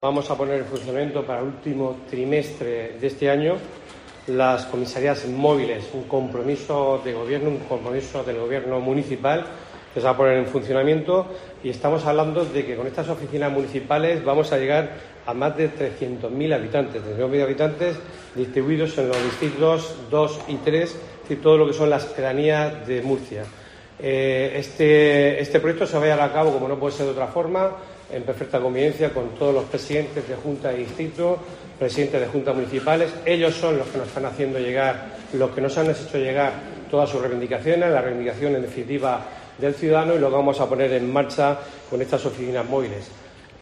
Fulgencio Perona, concejal de Seguridad Ciudadana y Emergencias